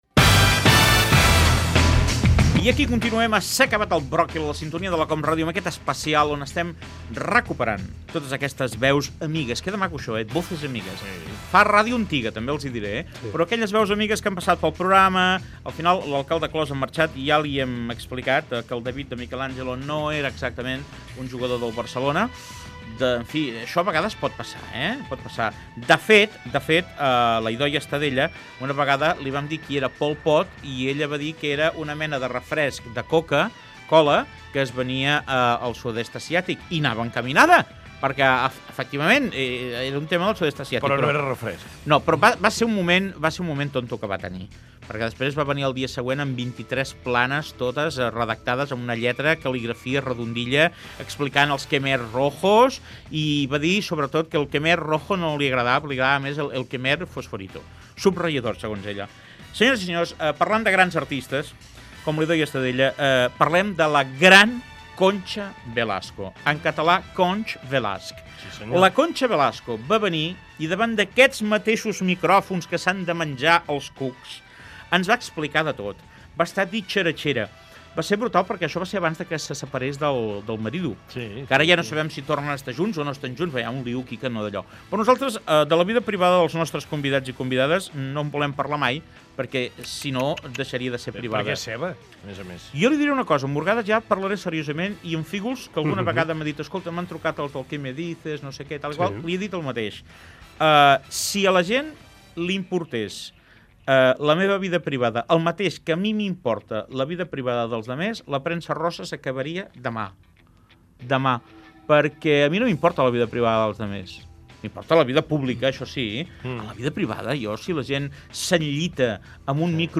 Comentaris sobre la intimitat dels famosos i veu de Concha Velasco
Entreteniment
FM